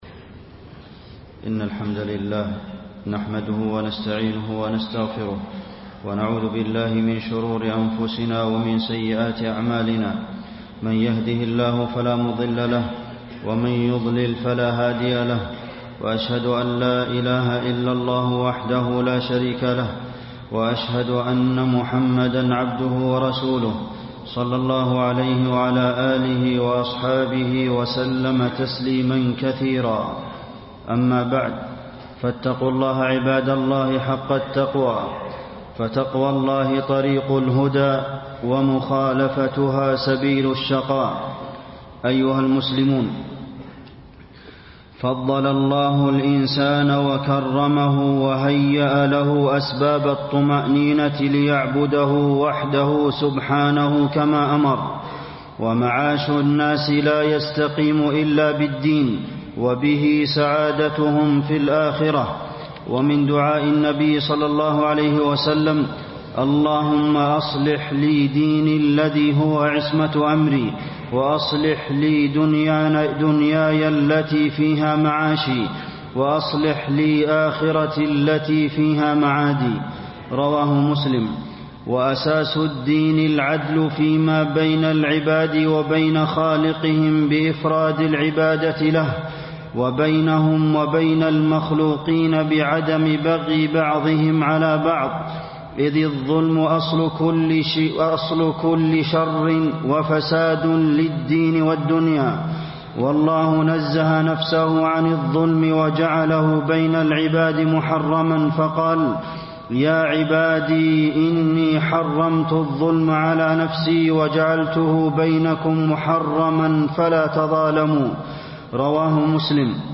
تاريخ النشر ٣٠ ربيع الثاني ١٤٣٣ هـ المكان: المسجد النبوي الشيخ: فضيلة الشيخ د. عبدالمحسن بن محمد القاسم فضيلة الشيخ د. عبدالمحسن بن محمد القاسم الظلم ظلمات يوم القيامة